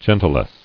[gen·ti·lesse]